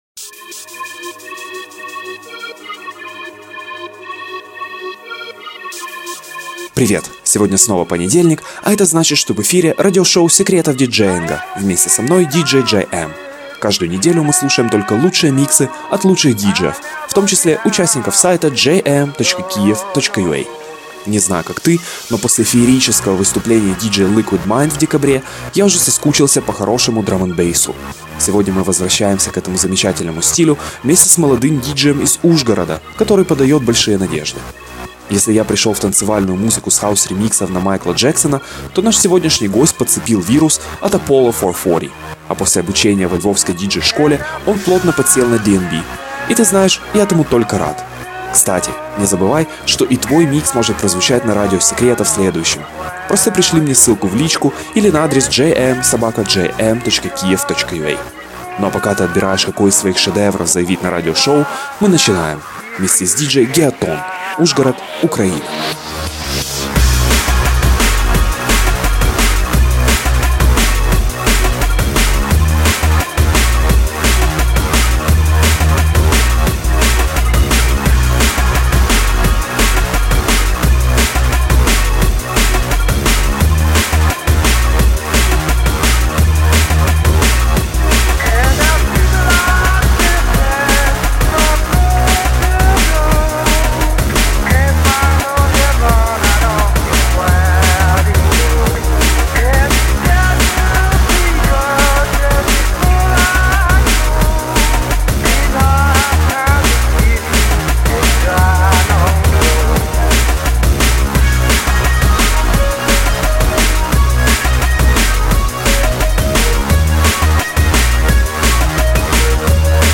еще одним часом музыки в этом стиле